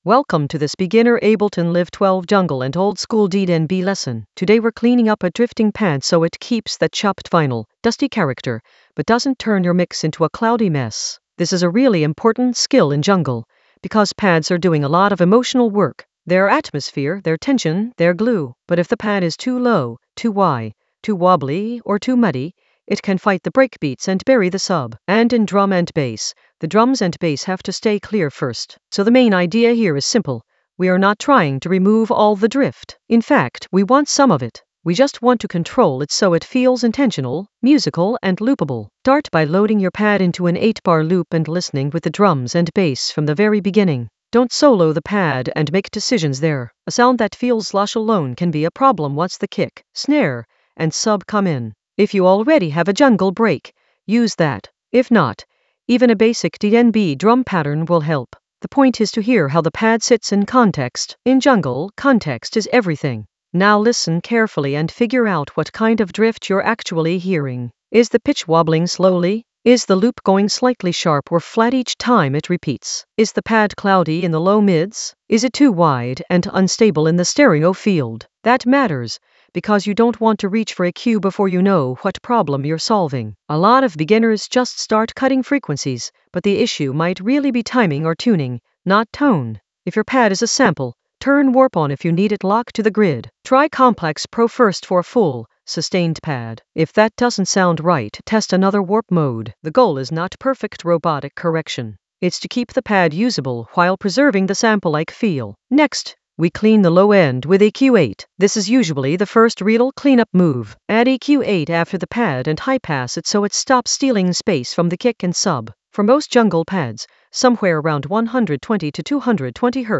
An AI-generated beginner Ableton lesson focused on Clean a jungle pad drift for chopped-vinyl character in Ableton Live 12 for jungle oldskool DnB vibes in the Groove area of drum and bass production.
Narrated lesson audio
The voice track includes the tutorial plus extra teacher commentary.